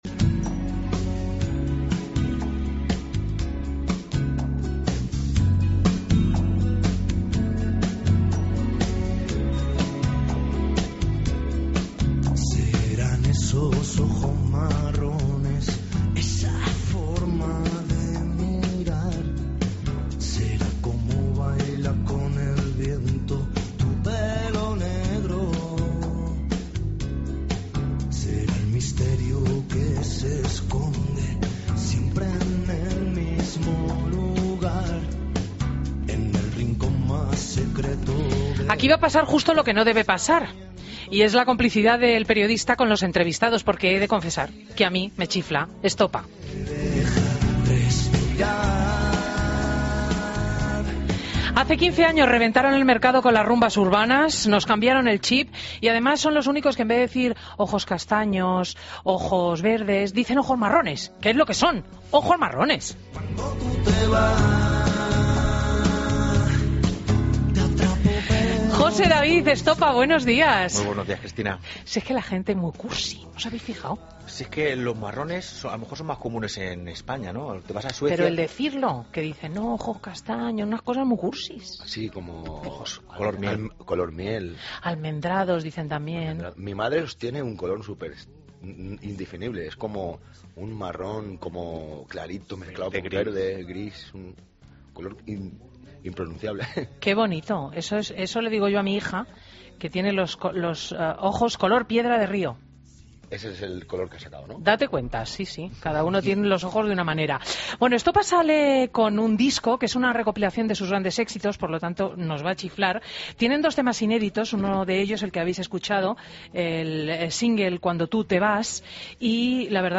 AUDIO: Entrevista a 'Estopa' en Fin de Semana COPE